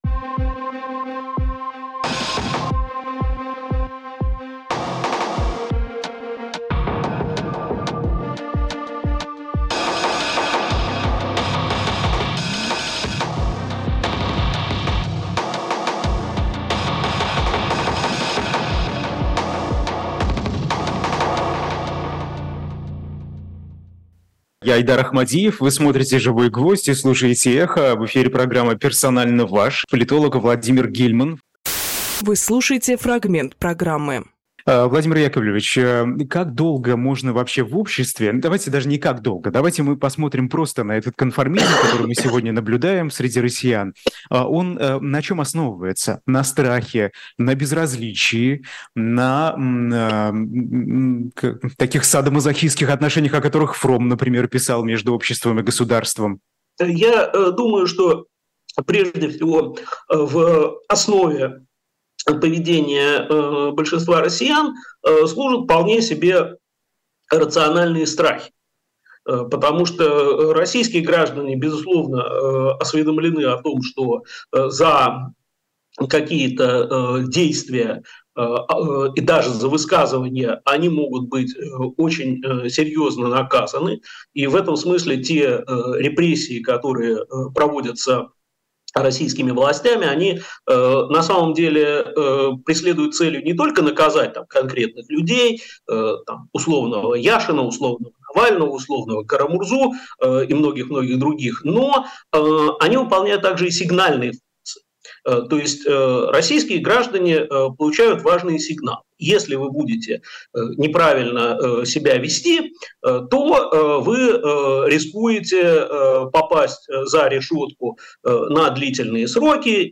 Владимир Гельманполитолог
Фрагмент эфира от 18.10.23